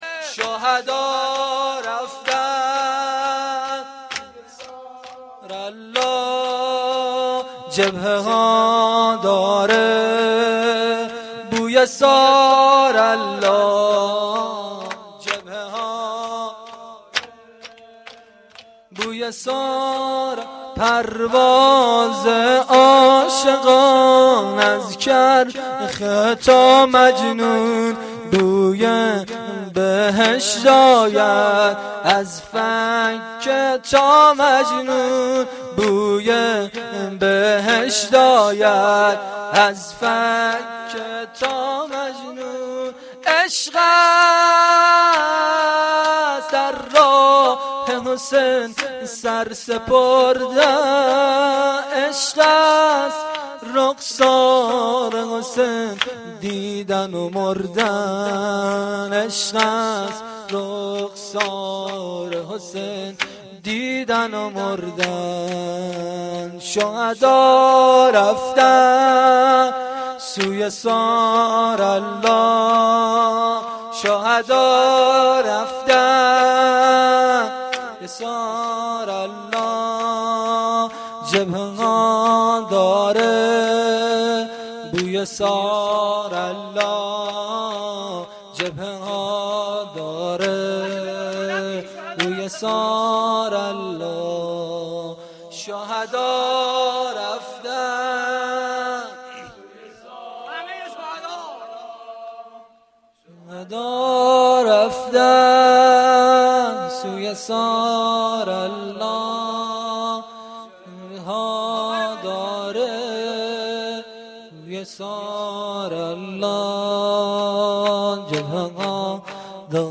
گزارش صوتی جلسه رحلت امام